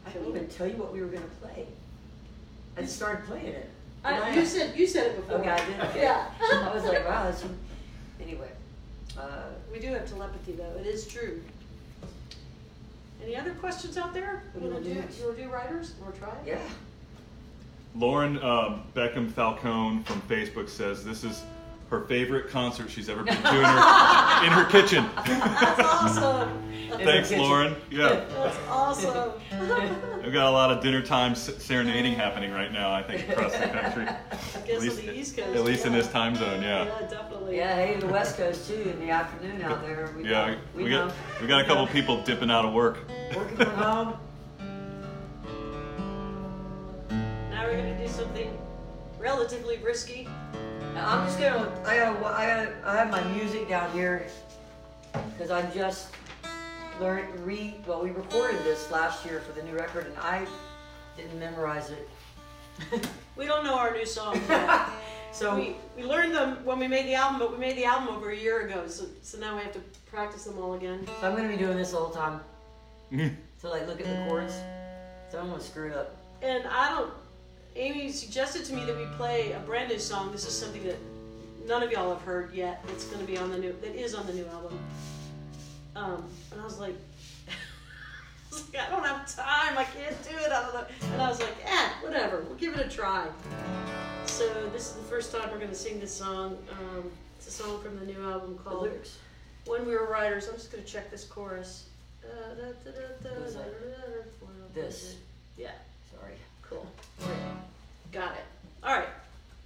lifeblood: bootlegs: 2020-03-19: facebook live online show
20. talking with the crowd (1:49)